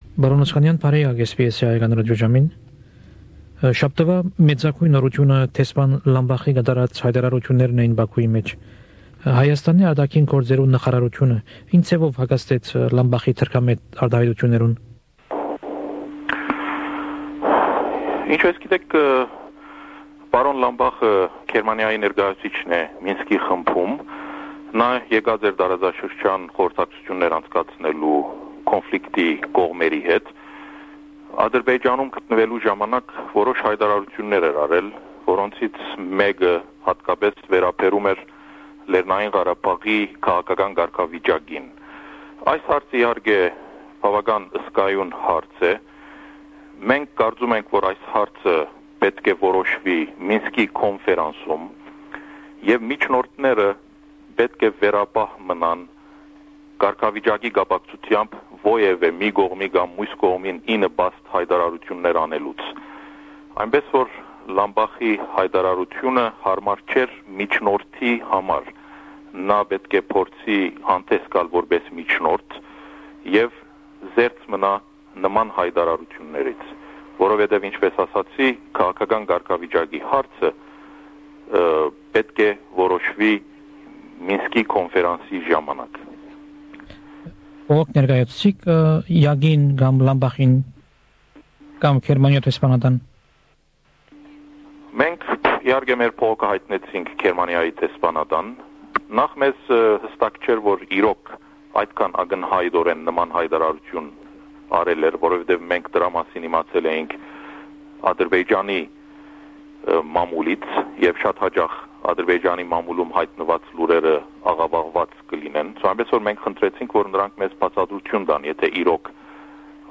This week from our archives we bring you an interview with then deputy foreign minister of Armenia Vartan Oskanian. Topics included Karabagh conflict, Armenia's foreign policy directions and relations with neighbouring countries.